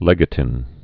(lĕgə-tĭn, -tīn)